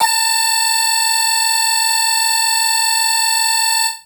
55bg-syn22-a5.wav